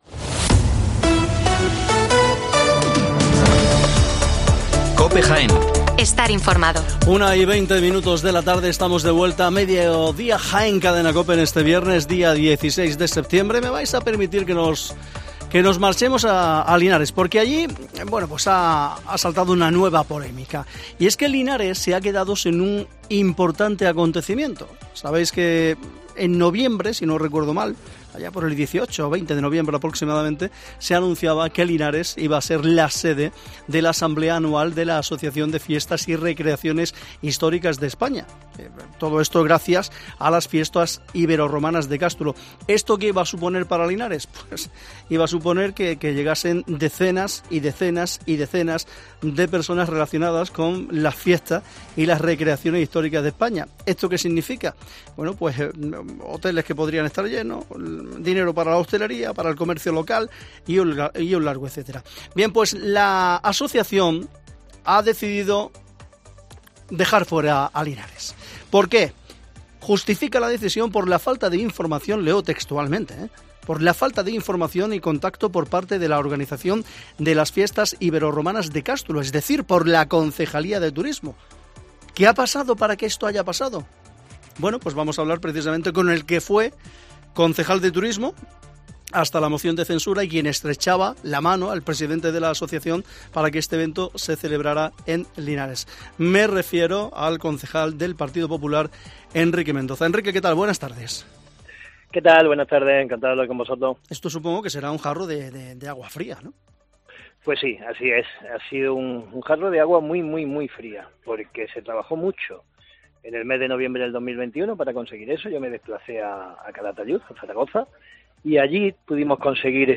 Enrique Mendoza, concejal del PP en Linares, nos habla sobre la cancelación de la asamblea anual de la AEFRH